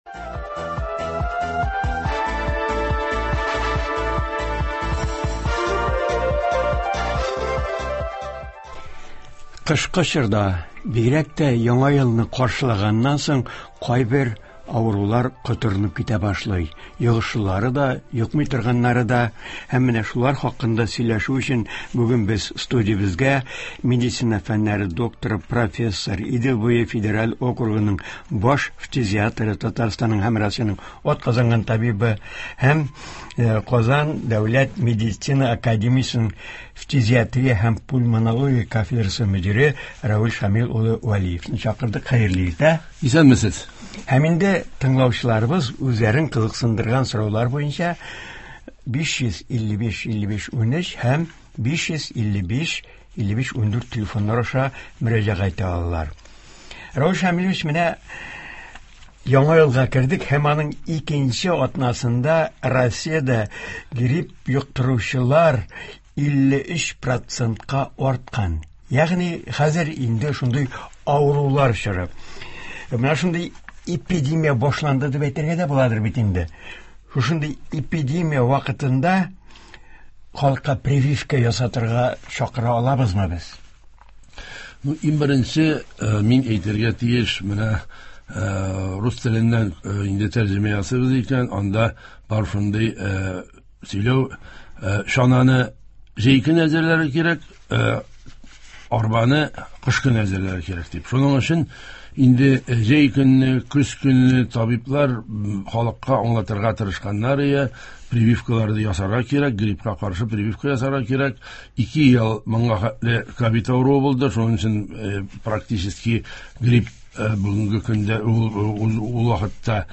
Бу сорауларга һәм тыңлаучылар мөрәҗәгатьләренә турыдан-туры эфирда медицина фәннәре докторы